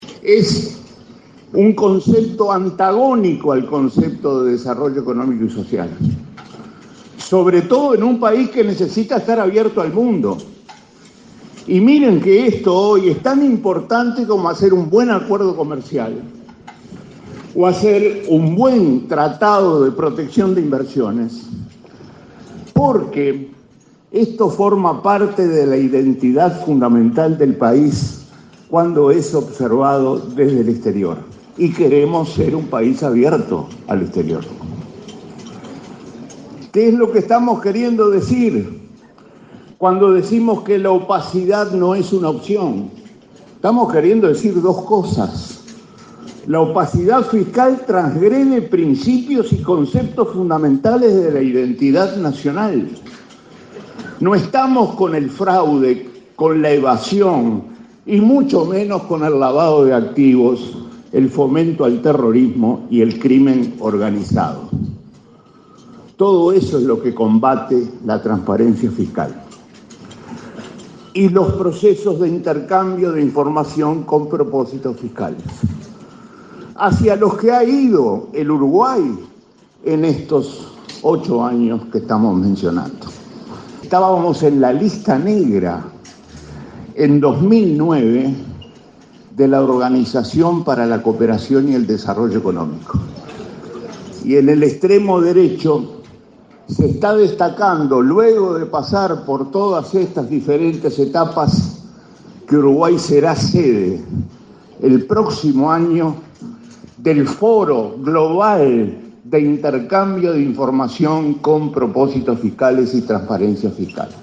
En los últimos ocho años, Uruguay no solo logró salir de la “lista negra” de la OCDE, sino que en 2018 será sede del foro global de intercambio de información con propósito fiscal y transparencia fiscal, señaló el ministro de Economía, Danilo Astori, en su disertación en ADM.